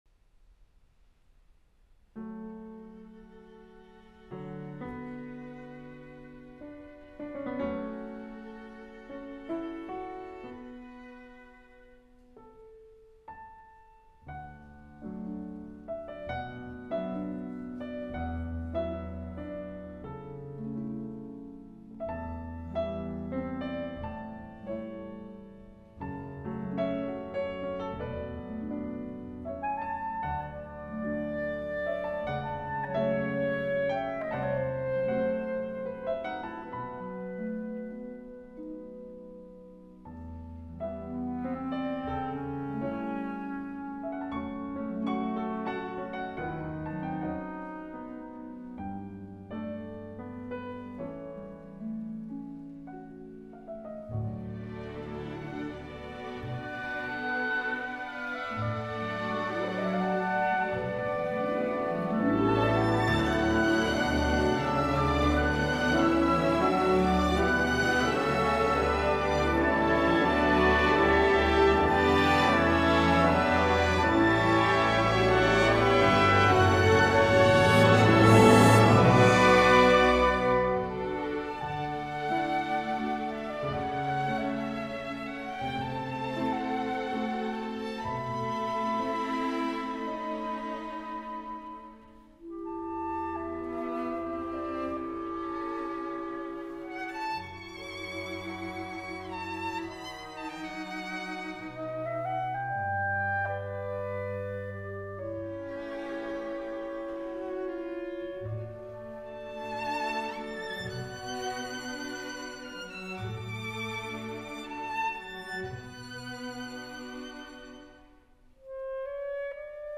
live performed